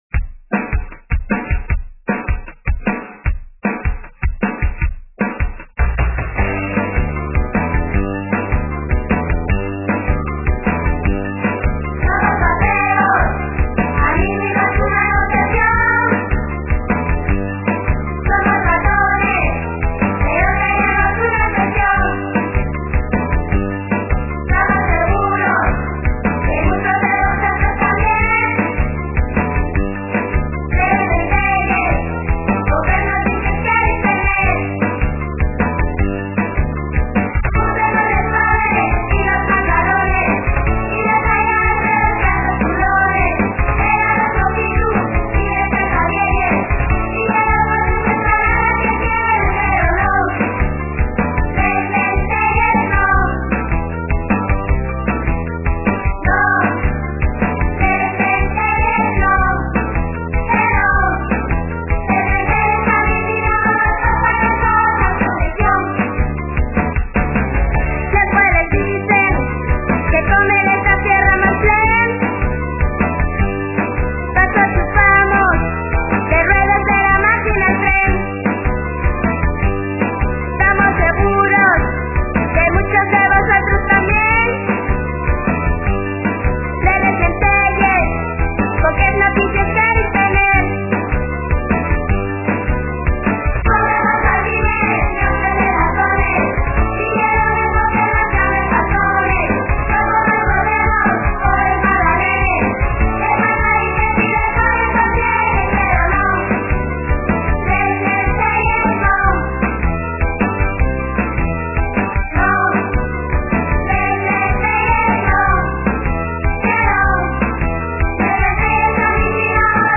Son toos escolinos del C.P de Lieres. (Siero) La música
guitarres